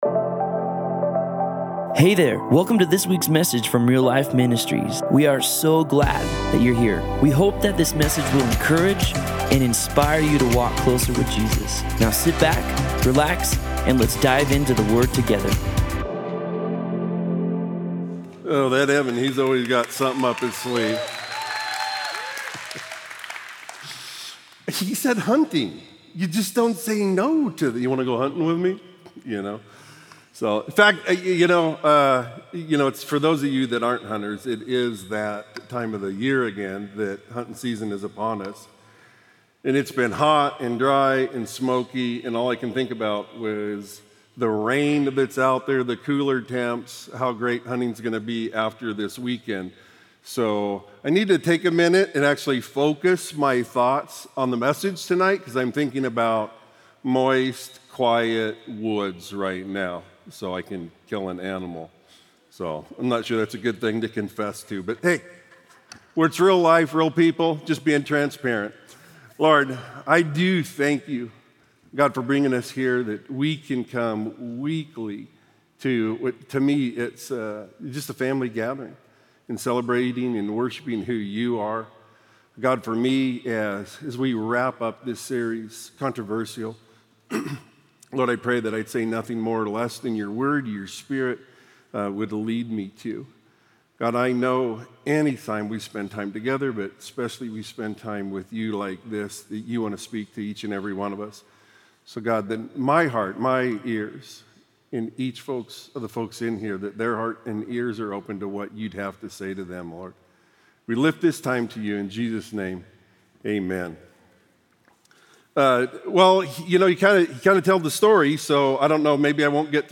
Click to Hear the Sunday Evening Service